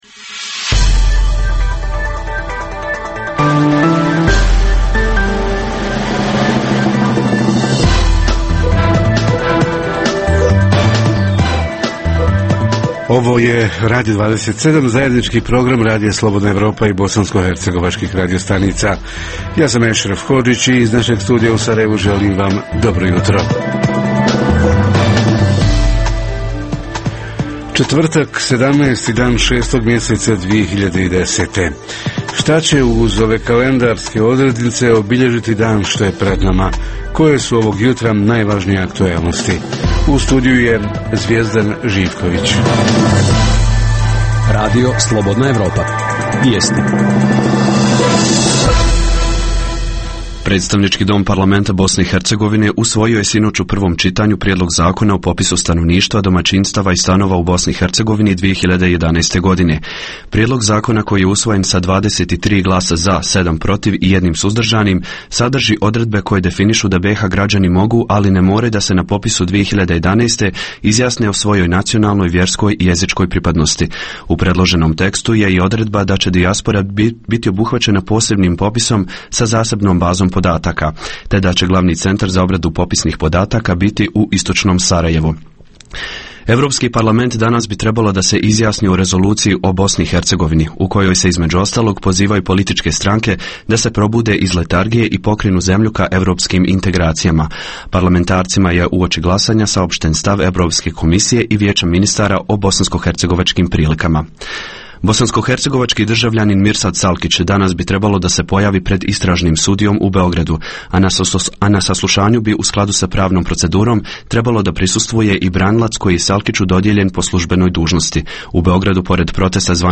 Ljetnji raspust i sezonski poslovi za mlade – gdje i šta srednjoškolci i studenti mogu raditi i koliko zaraditi? Reporteri iz cijele BiH javljaju o najaktuelnijim događajima u njihovim sredinama.
Redovni sadržaji jutarnjeg programa za BiH su i vijesti i muzika.